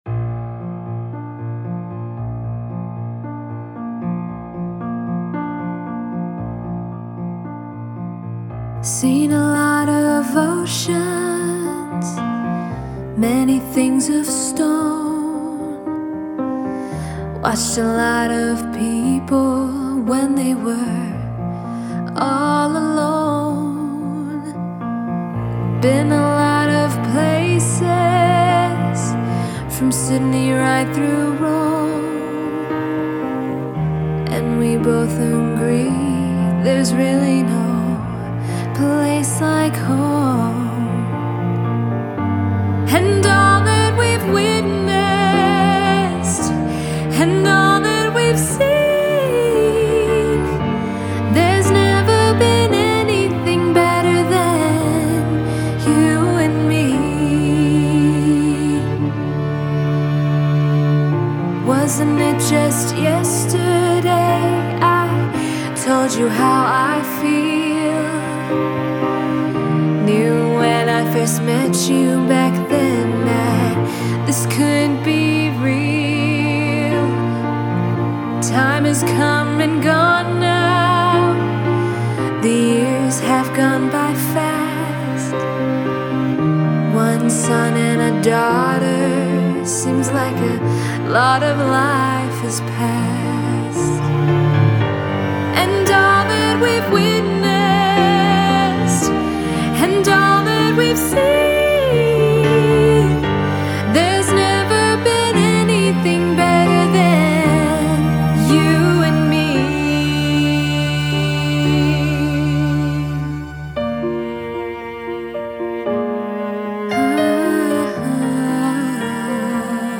a beautiful adult contemporary ballad
We decided to go with a more stripped down production focusing on a grand piano with lush live string arrangements including a quartet of cello, violin and viola.